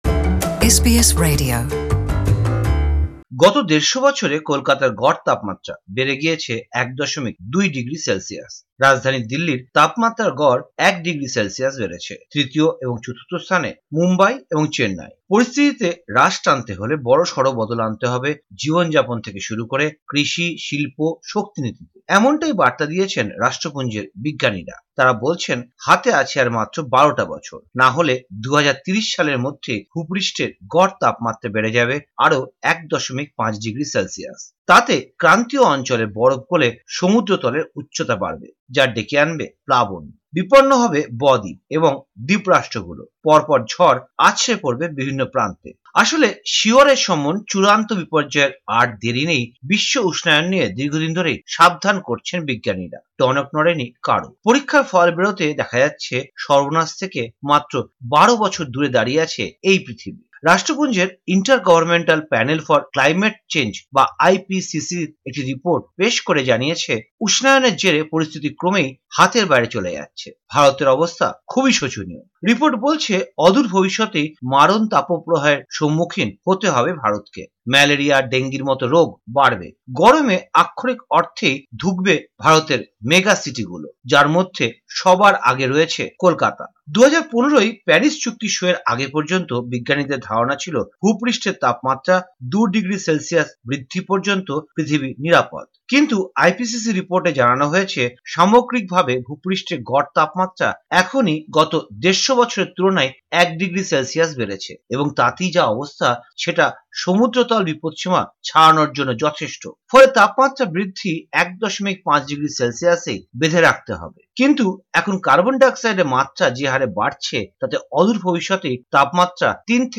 প্রতিবেদনটি বাংলায় শুনতে উপরের অডিও প্লেয়ারে ক্লিক করুন।